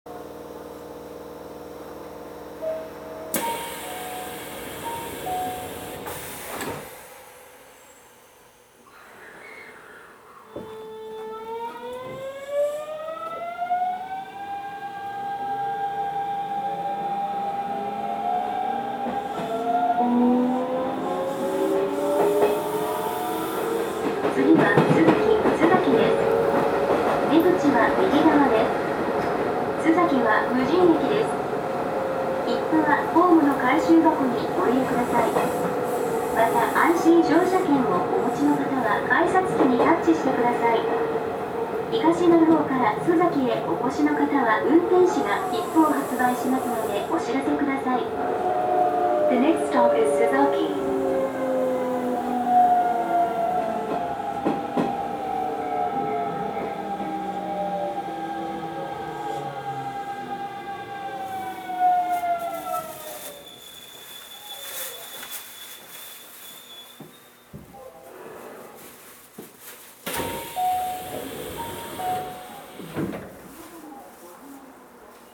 走行音
録音区間：東鳴尾～洲先(お持ち帰り)